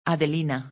Spanish names tend to always have a melodic sound that rolls off the tongue.